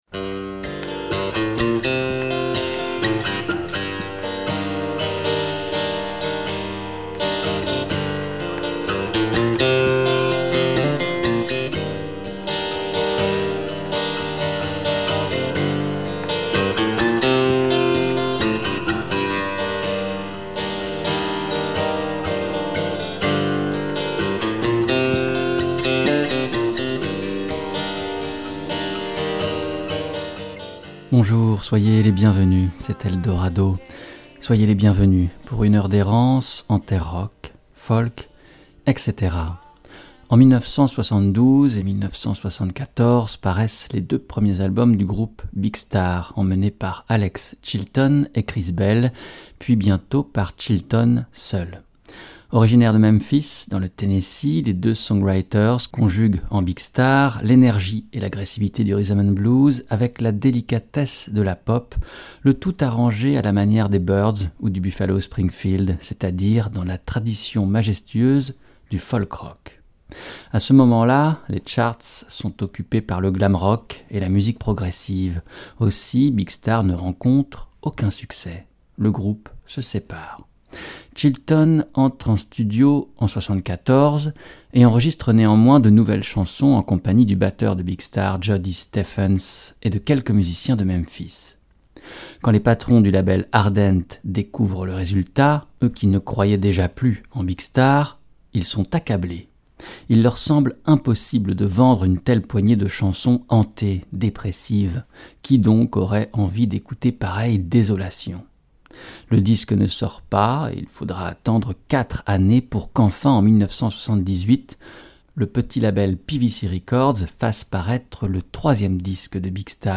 RADAR, La Radio d'Art en Sort